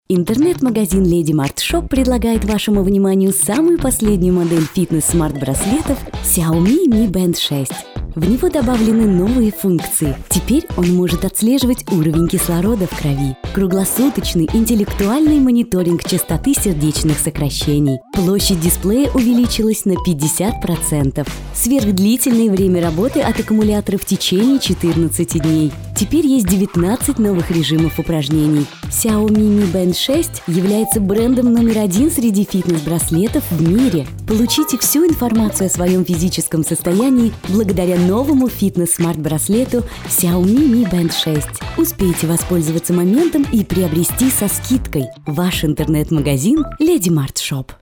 Статус: Диктор доступен для записи.
Микрофон студийный AKG C214 professional large diaphragm condencer , звуковой интерфейс UR44, поп фильтр, звуковой экран.